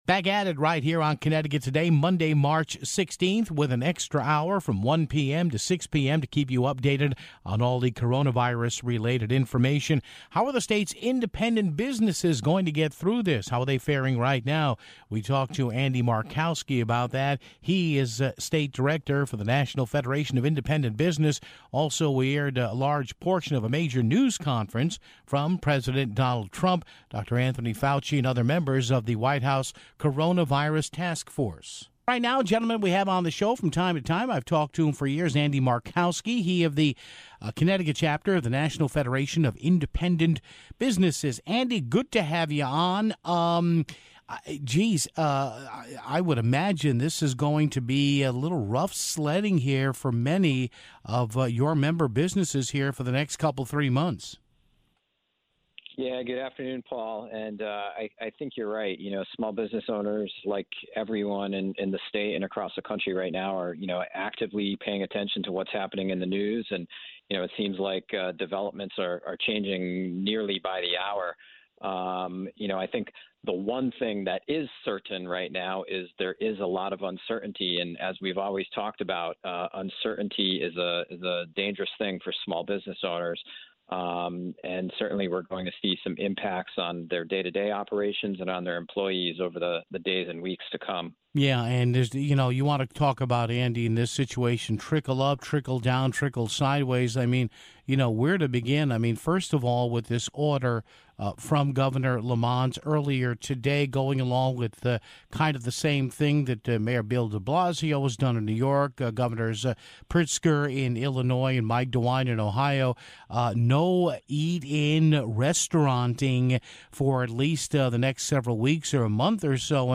We also carried a Press Conference that was held by President Donald Trump